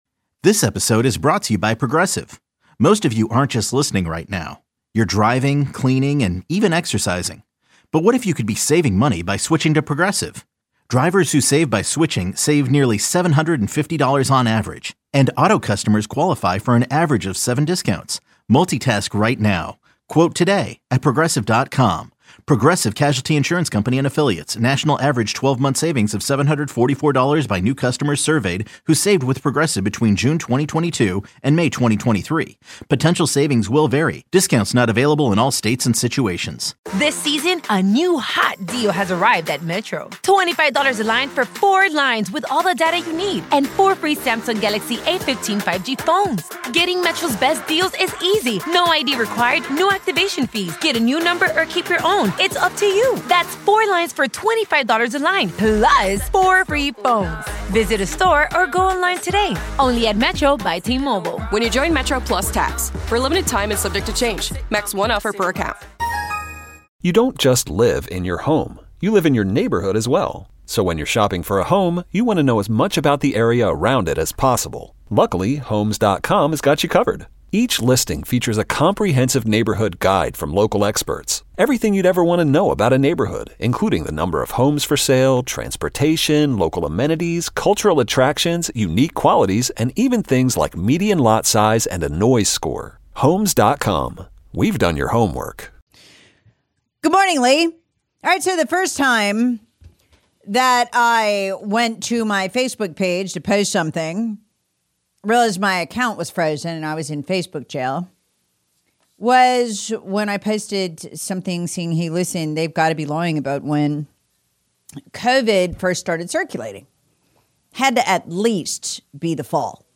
This explosive monologue uncovers what the mainstream media and government tried to keep hidden—from early COVID cases in U.S. service members returning from Wuhan, to the Biden administration’s suppression of key intelligence.